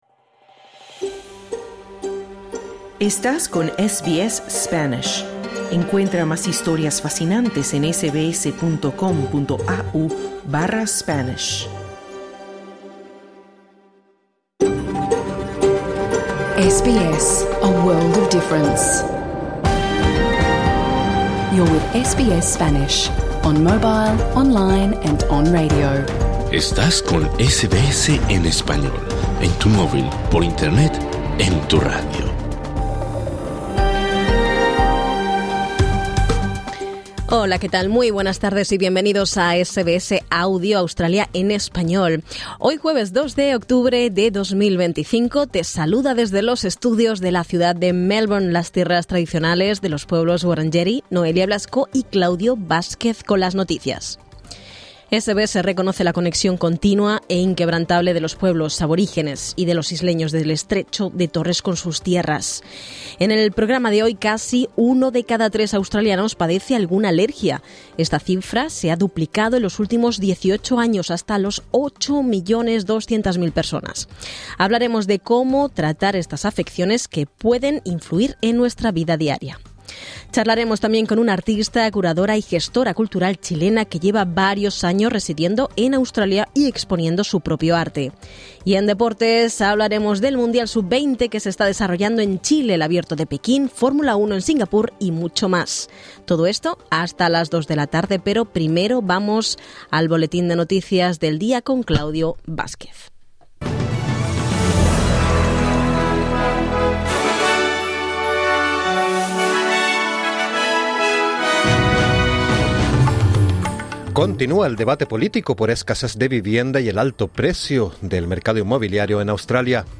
Uno de cada tres australianos sufre alergias. Exploramos su impacto y tratamiento. Conversamos con una artista chilena sobre su carrera en Australia, y repasamos lo último en deportes: Sub20, Abierto de Pekín, F1 en Singapur, entre otras noticias.